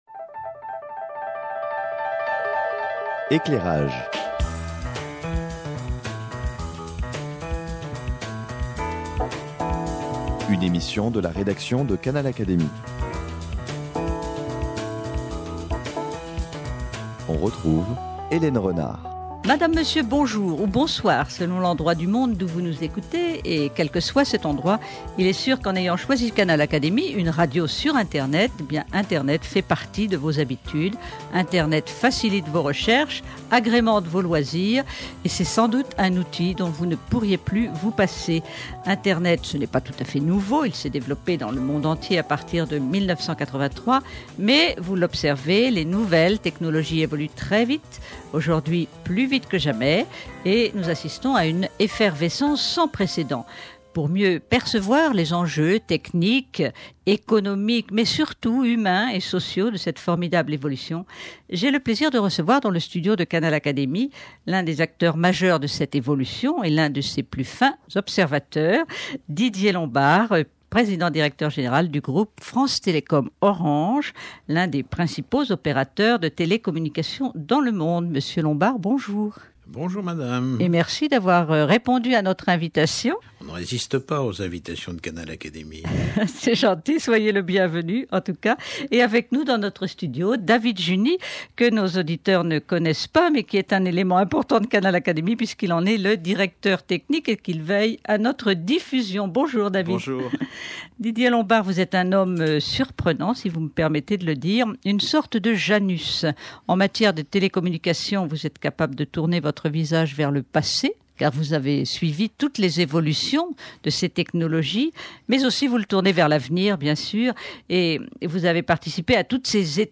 Mieux percevoir les enjeux techniques, économiques, humains et sociaux de la formidable évolution des nouvelles technologies, tel est le thème de cet entretien avec Didier Lombard, PDG du groupe France-Télécom-Orange, l’un des acteurs majeurs de cette évolution et l’un de ses plus fins observateurs.